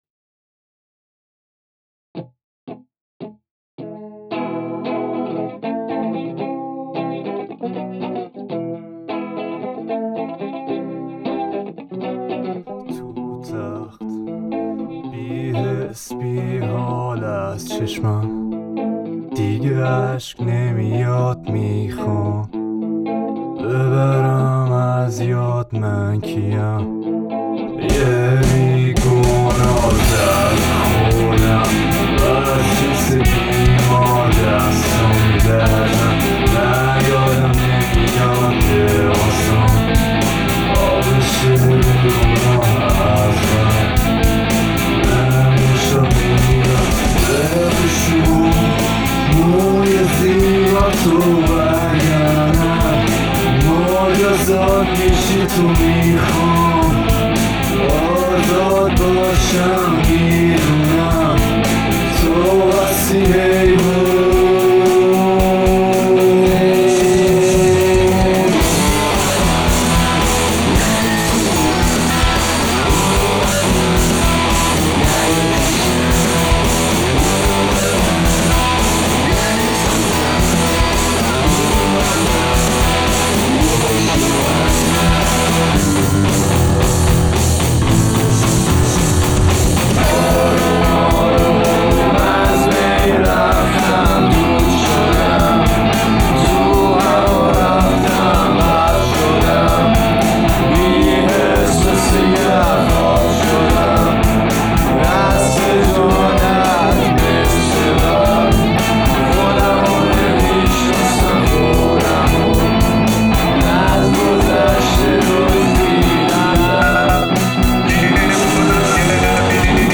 Persian rock راک ایرانی